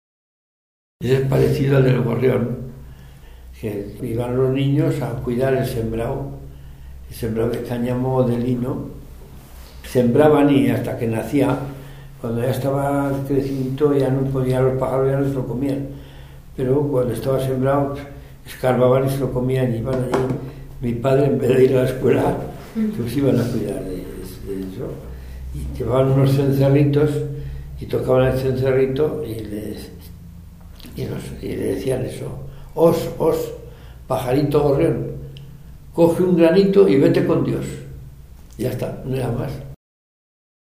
Clasificación: Oraciones
Lugar y fecha de recogida: Logroño, 6 de marzo de 2017